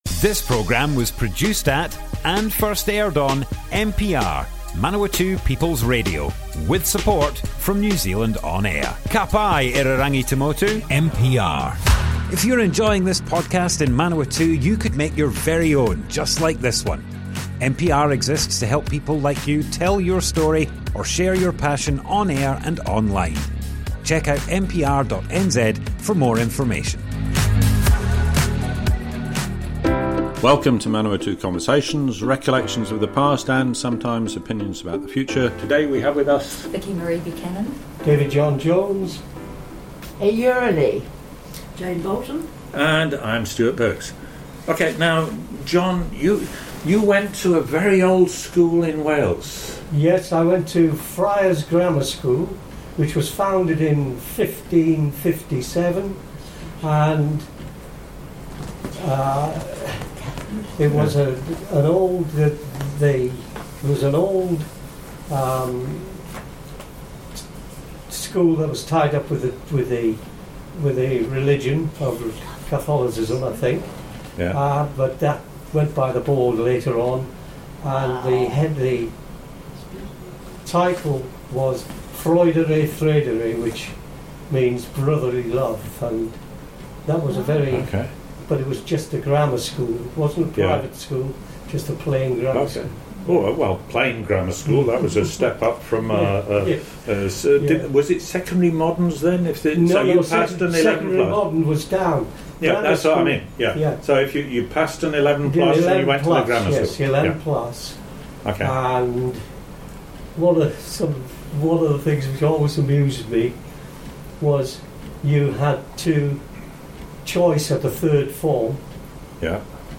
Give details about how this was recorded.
Manawatu Conversations Object type Audio More Info → Description Broadcast on Manawatu People's Radio, 13th September 2022. 2 of 3.